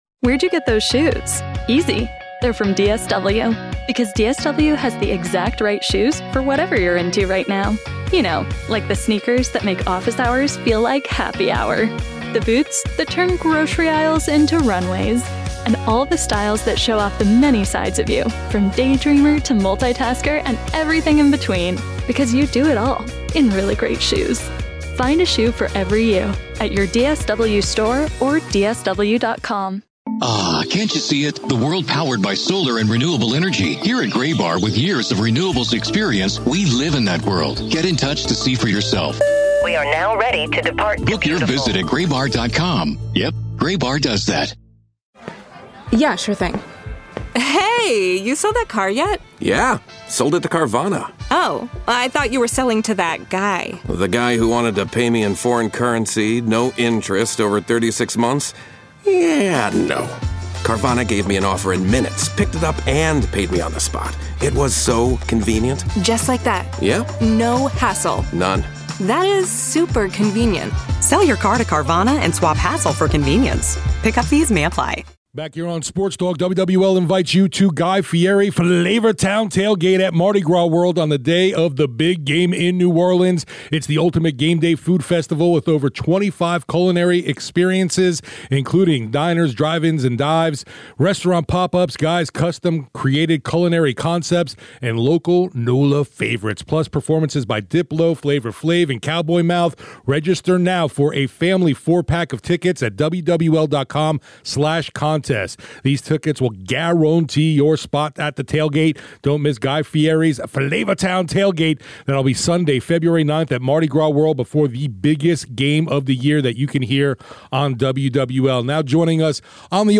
Saints interviews, press conferences and more